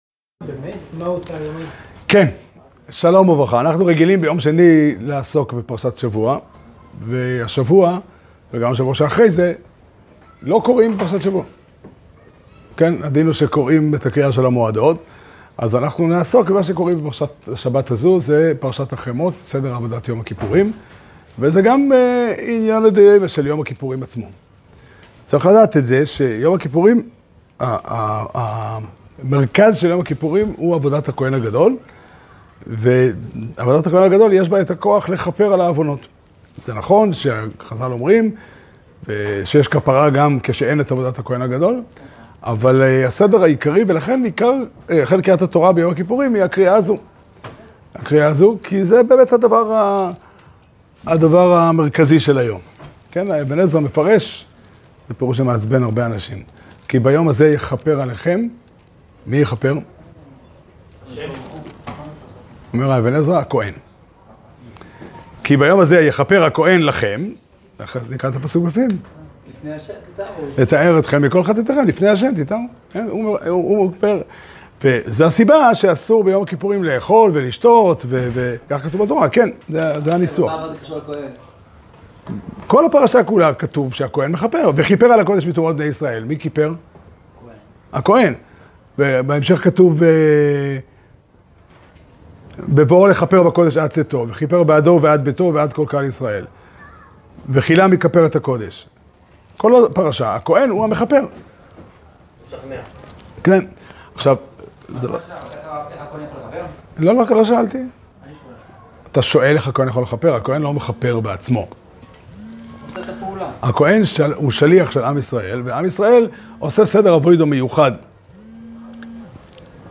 שיעור שנמסר בבית המדרש פתחי עולם בתאריך ו' תשרי תשפ"ה